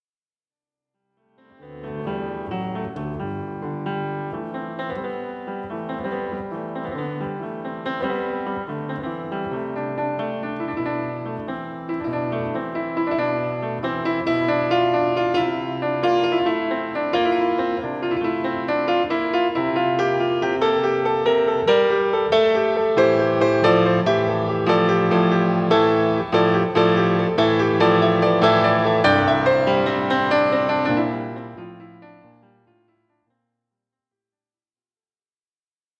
Entre grégorien et gospel, le piano chante Marie.